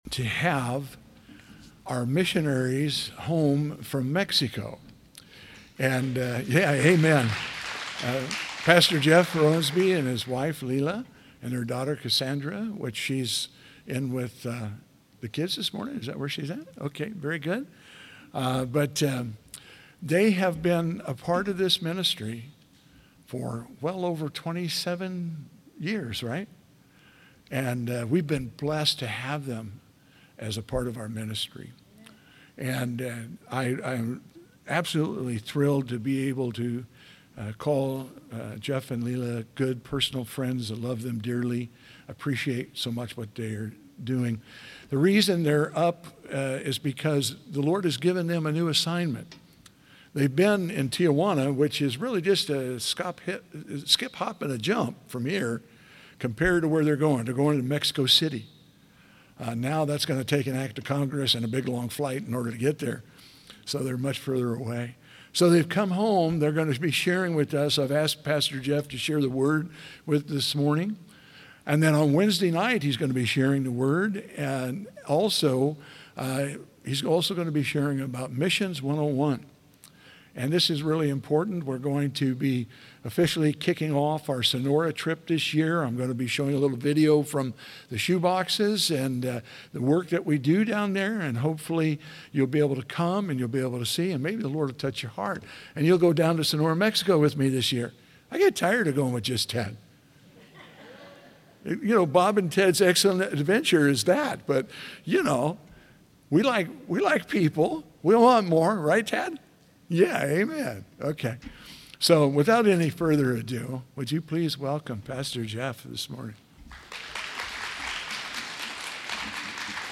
CCS Sermons podcast